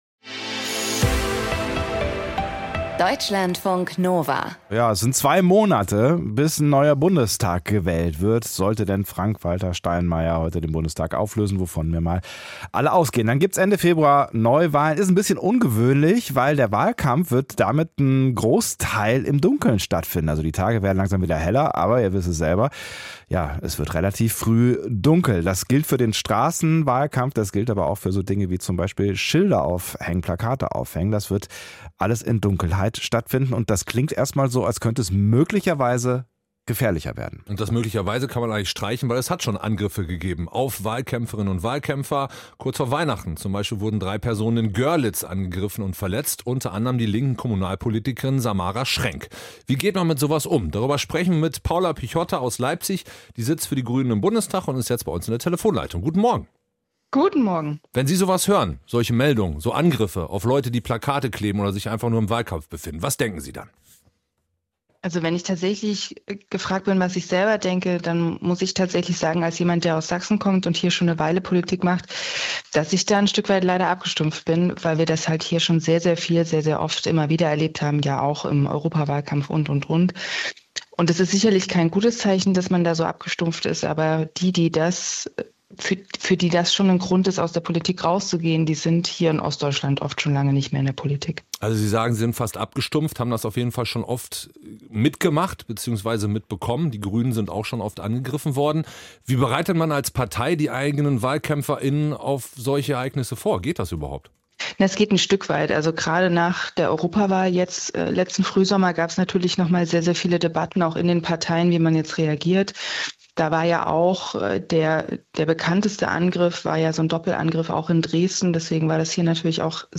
Gut recherchiert, persönlich erzählt – das ist die Reportage von Deutschlandfunk Kultur.